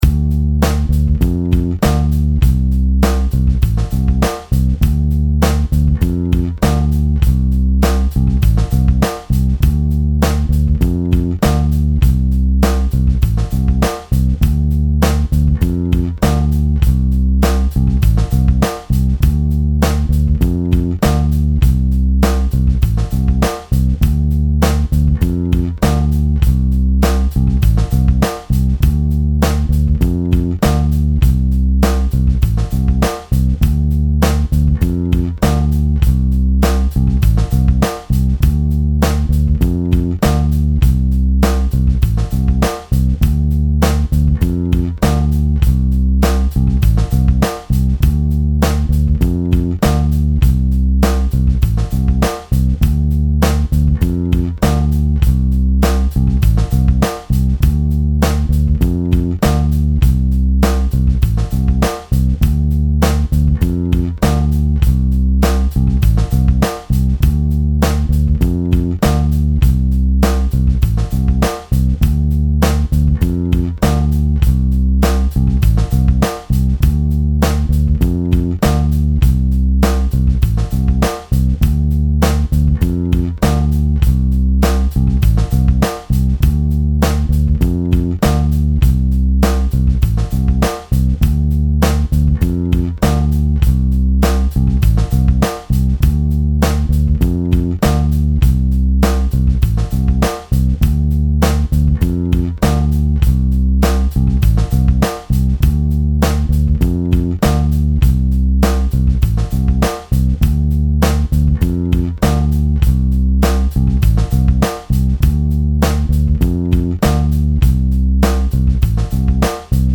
Full Tempo: 100 bpmFull Tempo: 100 bpm
100 Rhythm Section